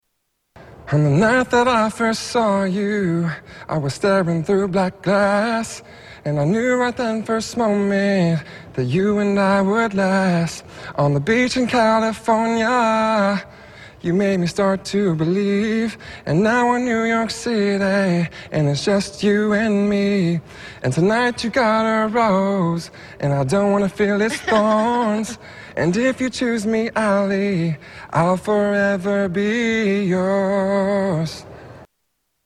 Category: Television   Right: Personal
Reality Show